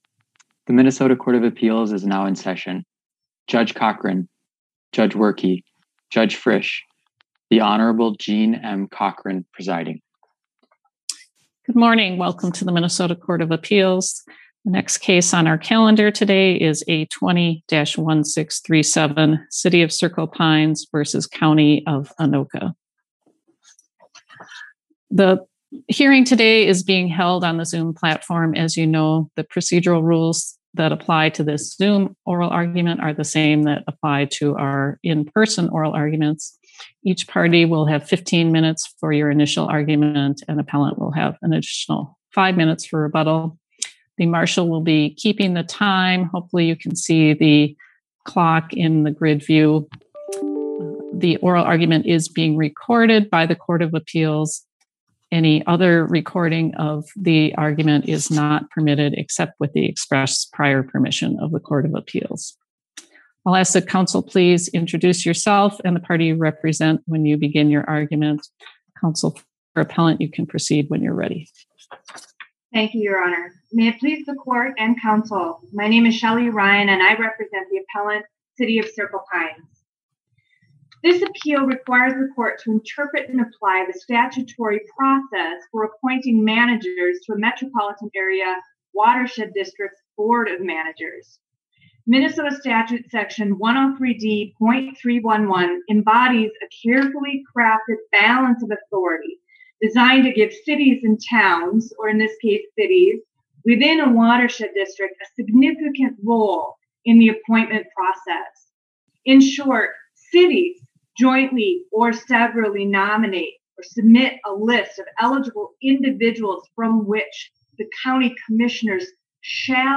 Minnesota Court of Appeals Oral Argument Audio Recording